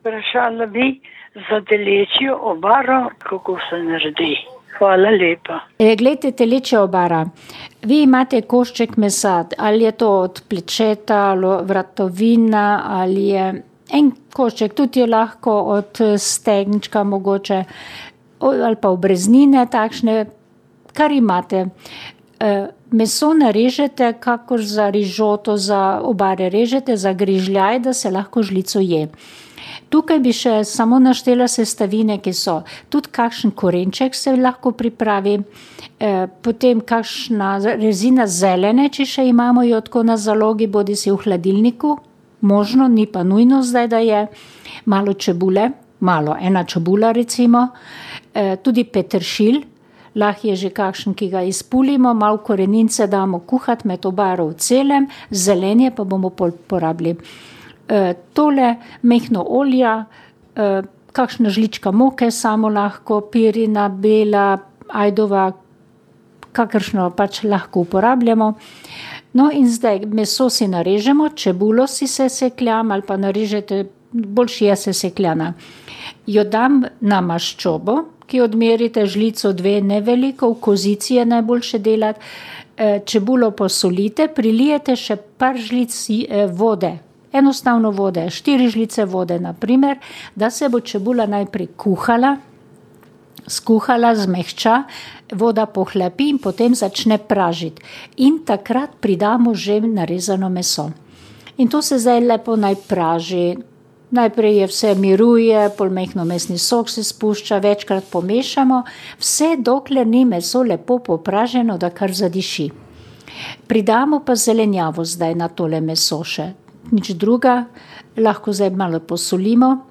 Na isti način lahko naredimo tudi piščančjo obaro. Poslušalka je dodala, da obare ne zgosti z moko ampak z drobtinami, ki jih praži na maslu.